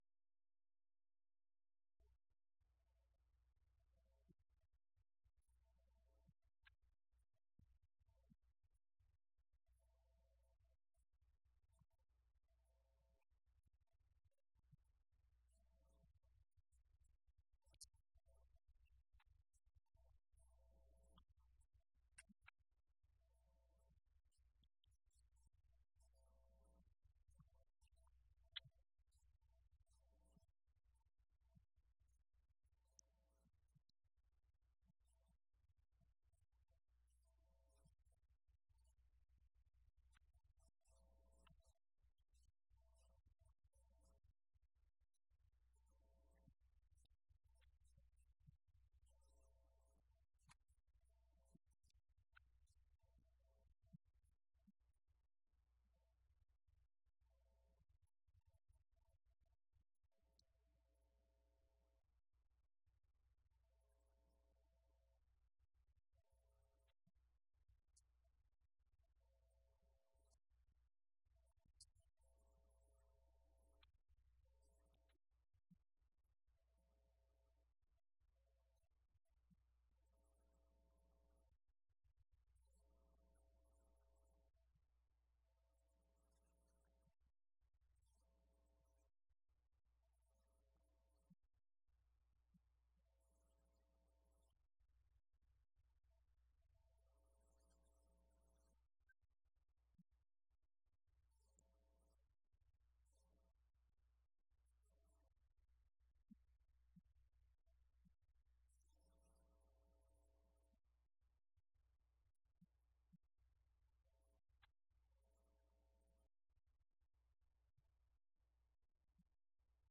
Series: Schertz Lectureship Event: 17th Annual Schertz Lectures Theme/Title: Studies in Job